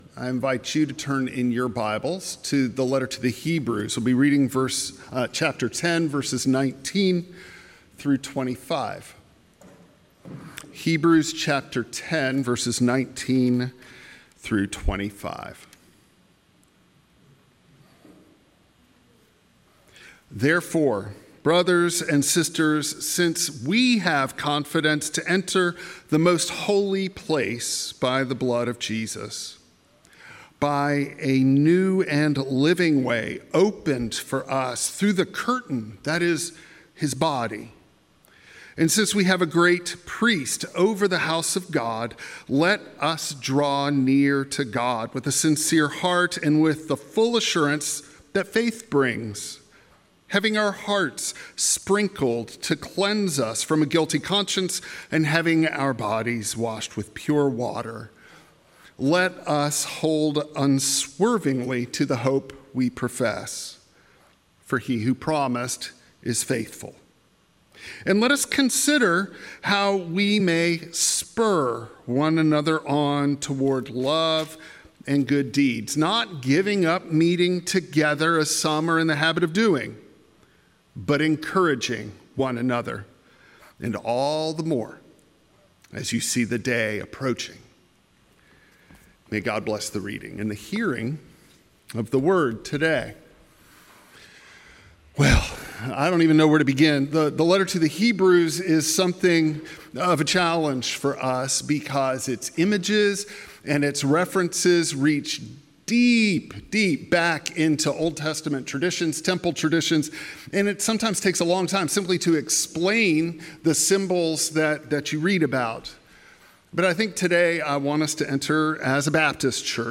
Hebrews 10:19-25 Service Type: Traditional Service What if the Spirit’s nudge feels uncomfortable?